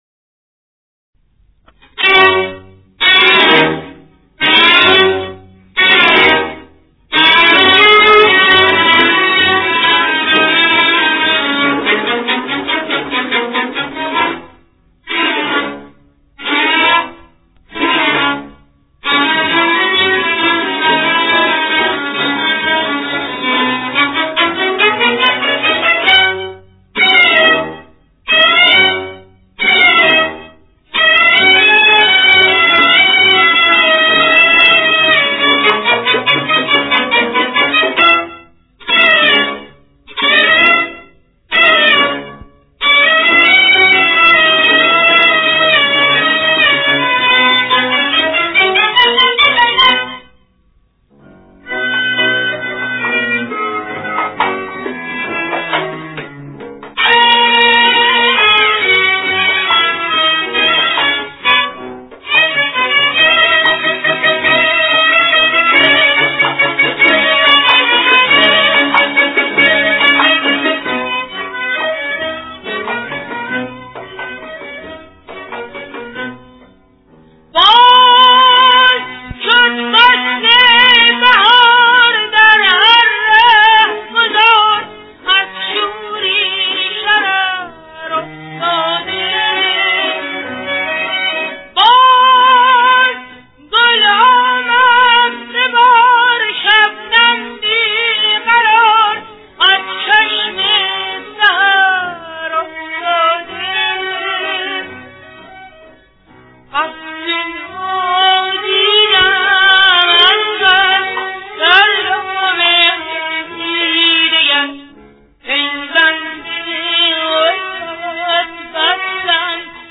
در مقام: ماهور